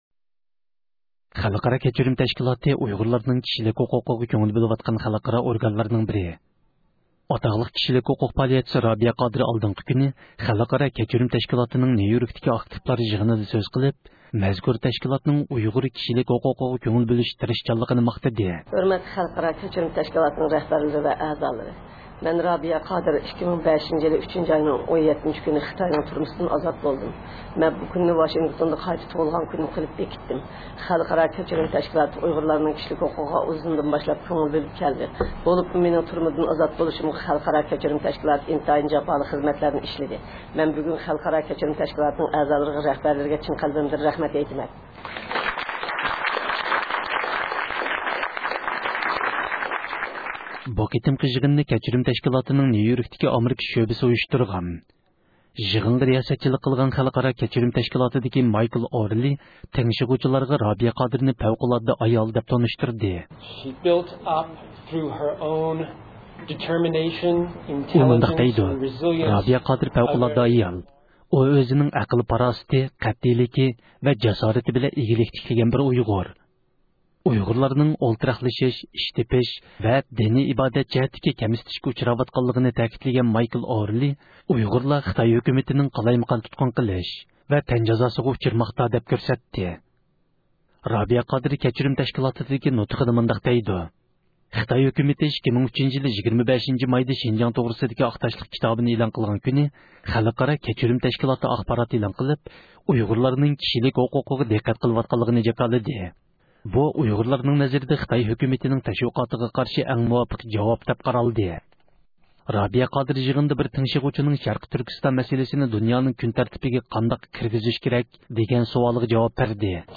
رابىيە قادىر، خەلقئارا كەچۈرۈم تەشكىلاتىنىڭ نيۇ – يوركتىكى يىغىنىدا سۆز قىلدى – ئۇيغۇر مىللى ھەركىتى
بۇ قېتىمقى يىغىننى كەچۈرۈم تەشكىلاتىنىڭ نيۇ – يوركتىكى ئامېرىكا شۆبىسى ئۇيۇشتۇرغان.